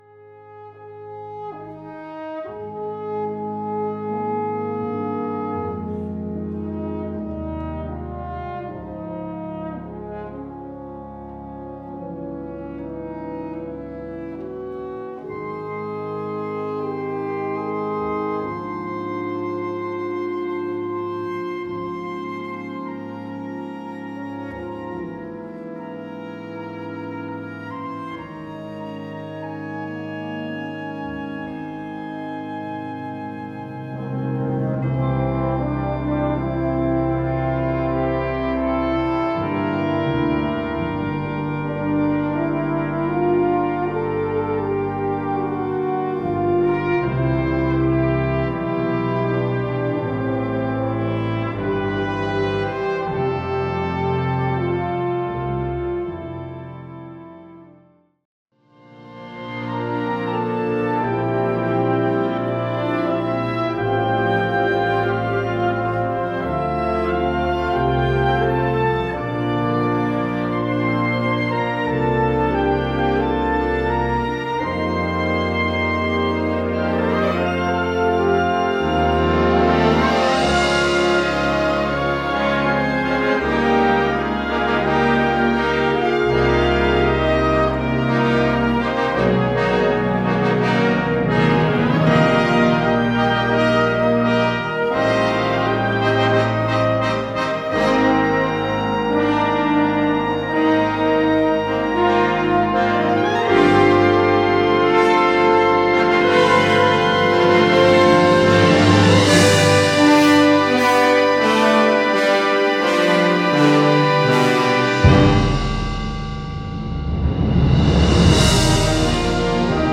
Gattung: Choral
Besetzung: Blasorchester
ergreifende Choral-Fantasie